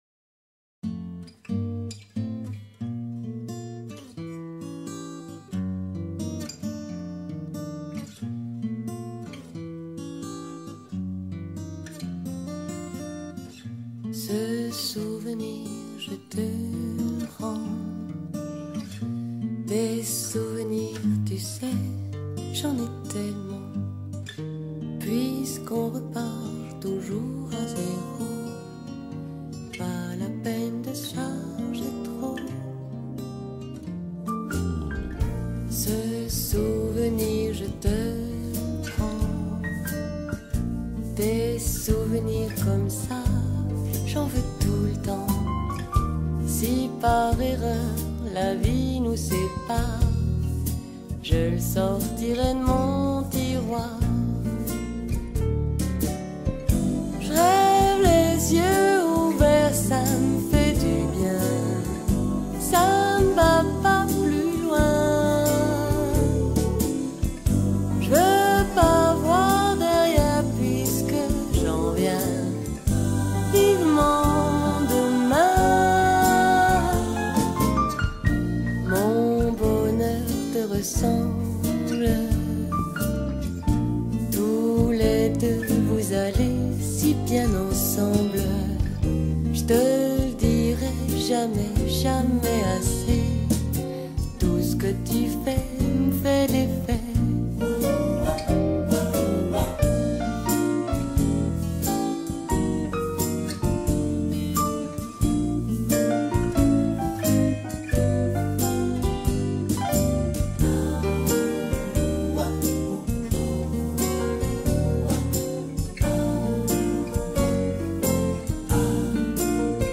Chœur d’hommes fondé en 1860